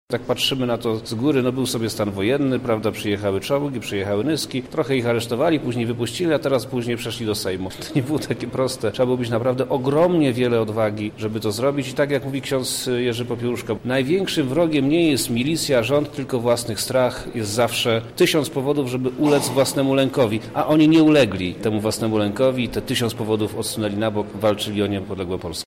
O stanie wojennym mówi Przemysław Czarnek, wojewoda Lubelski